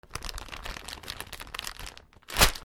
パンの袋を開ける 03
食事 ビニール